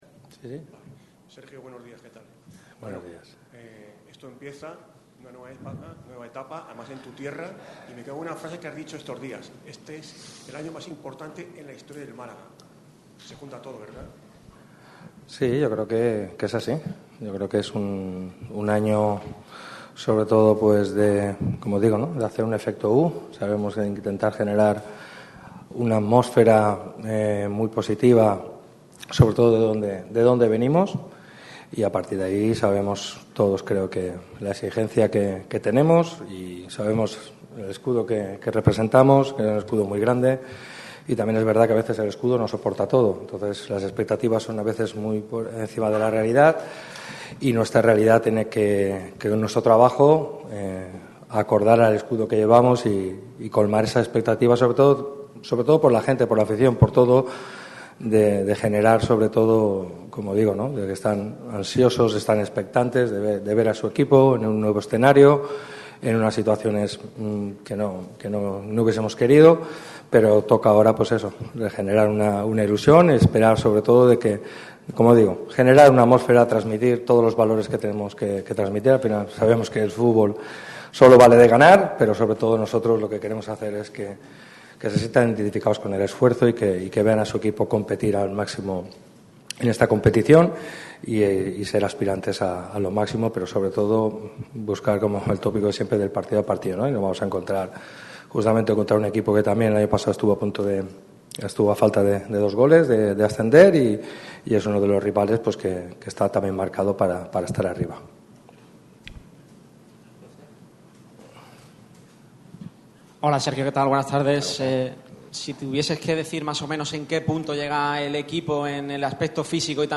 El entrenador malaguista ha atendido a los medios de comunicación en la previa del partido ante el Castellón. Pellicer cambia el chip: «Pedir fichajes se acabó».